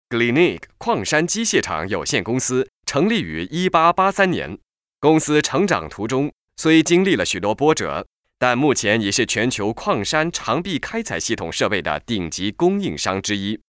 Professioneller chinesischer Sprecher für TV / Rundfunk / Industrie.
chinesischer Sprecher
Sprechprobe: Industrie (Muttersprache):
chinese voice over artist